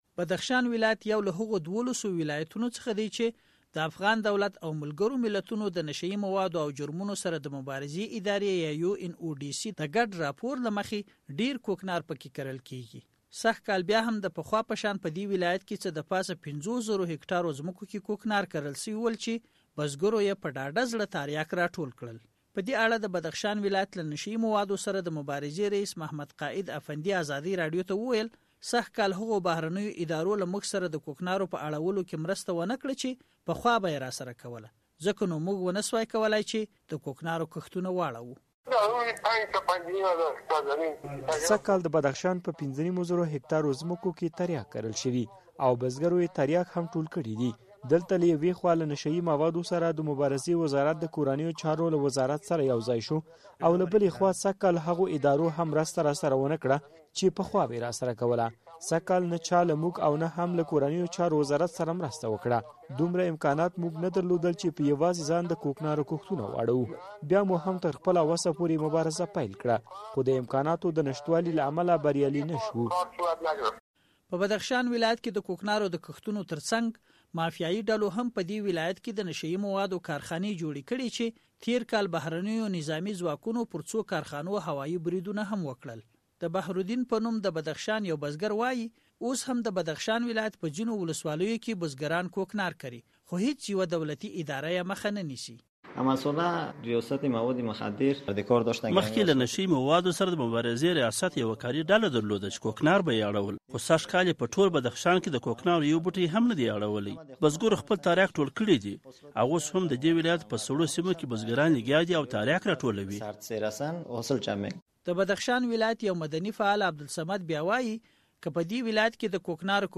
د بدخشان راپور